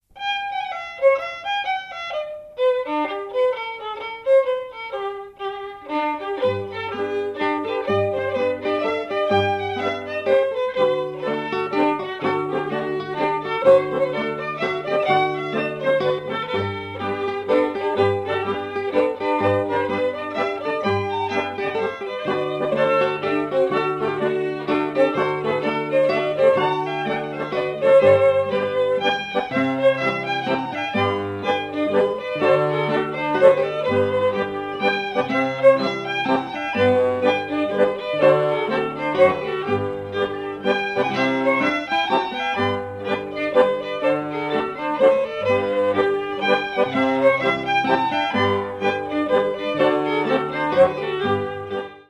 danse : mazurka
Pièce musicale éditée